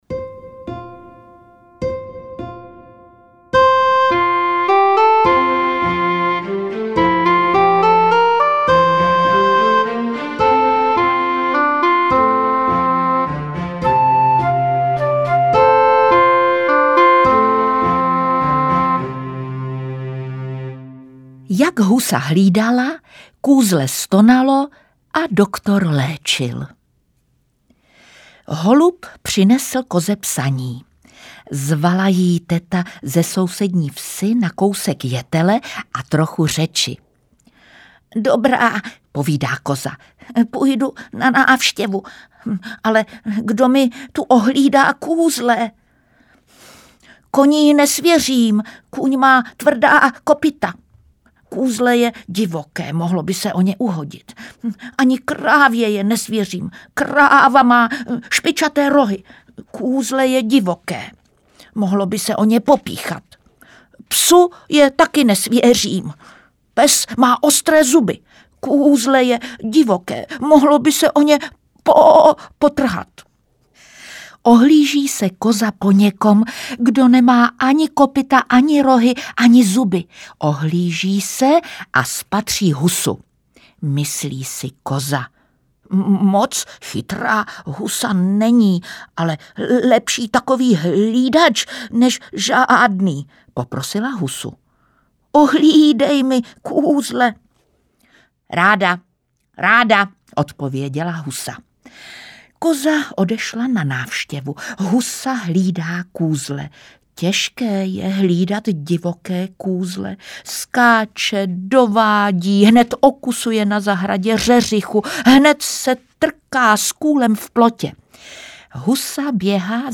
Kotě z Kocourkova audiokniha
Ukázka z knihy
• InterpretJitka Molavcová